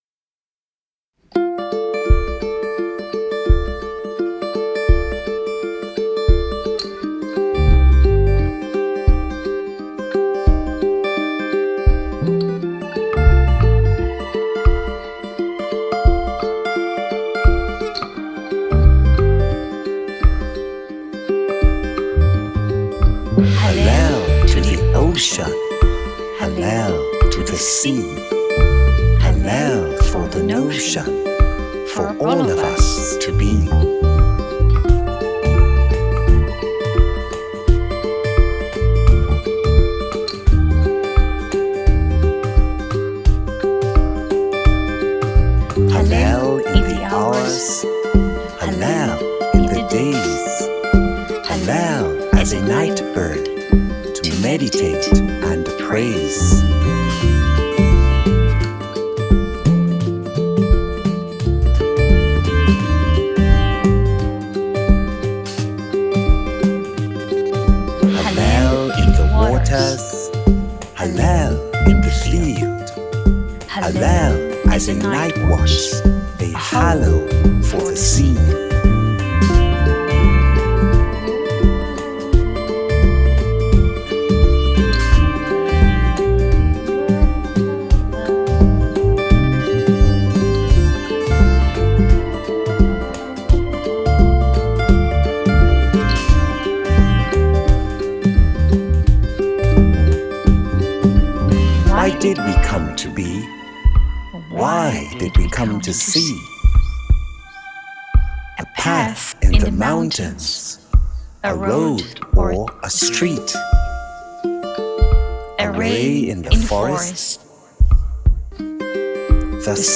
Guitar & Percussion
Bass
Vocals
Synth & Additional Vox